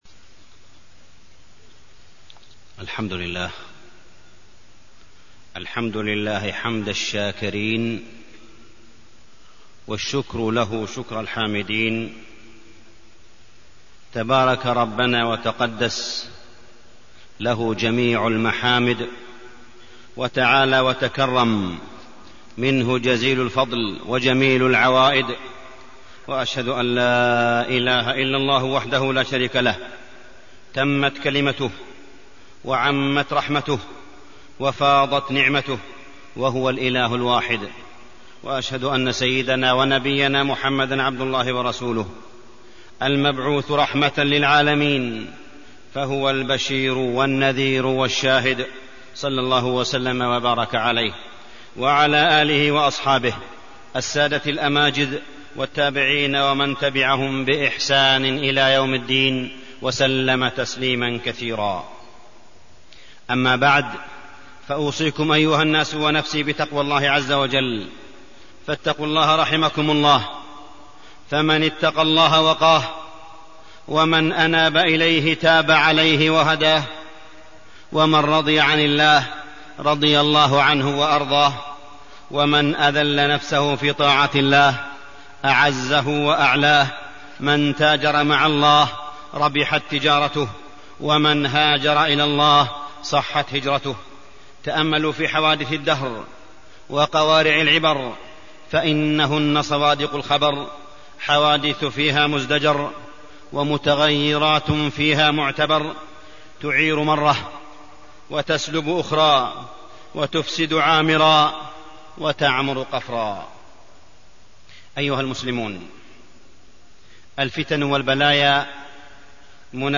تاريخ النشر ٢٠ شوال ١٤٢٢ هـ المكان: المسجد الحرام الشيخ: معالي الشيخ أ.د. صالح بن عبدالله بن حميد معالي الشيخ أ.د. صالح بن عبدالله بن حميد أثر المبادئ والنظم في حياتنا